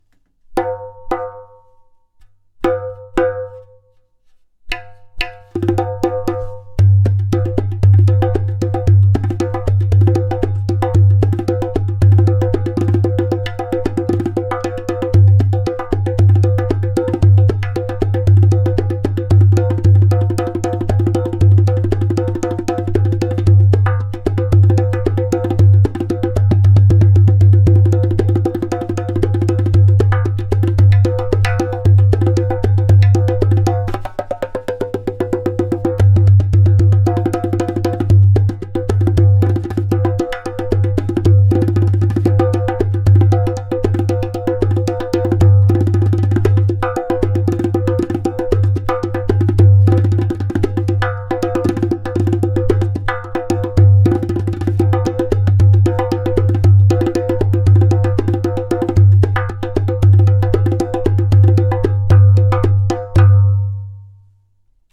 115 bpm:
In this line of darbukas materials like clay, glaze and natural skin met in a magical way which brings into life a balanced harmonic sound.
• Taks with harmonious overtones.
• Deep bass.
• Loud clay kik/click sound!